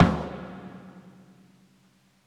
TOMZ8.wav